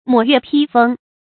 抹月批风 mǒ yuè pī fēng 成语解释 抹：细切；批：薄切。
成语繁体 抹月批風 成语简拼 mypf 成语注音 ㄇㄛˇ ㄩㄝˋ ㄆㄧ ㄈㄥ 感情色彩 中性成语 成语用法 联合式；作谓语、定语；含贬义 成语结构 联合式成语 产生年代 古代成语 近 义 词 风餐露宿 成语例子 游衍，抹月批风随过遣，痴云腻雨无留恋。